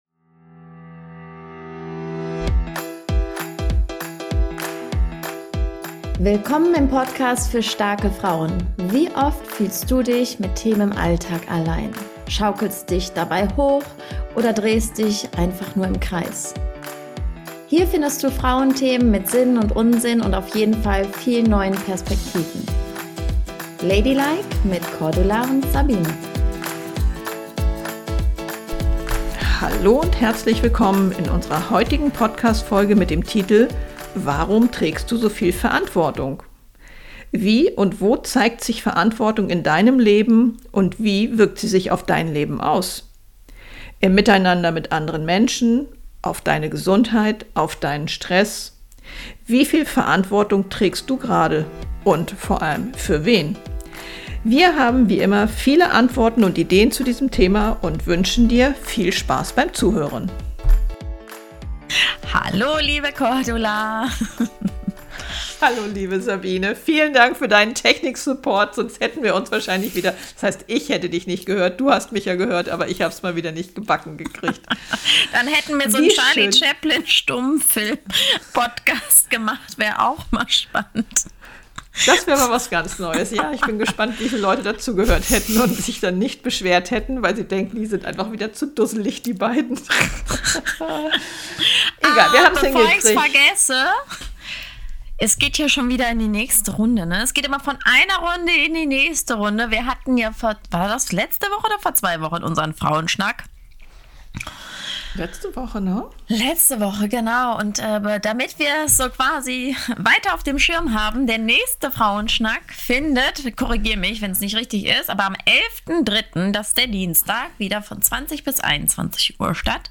Entschuldige die Verspätung der Folge und die Tonqualität.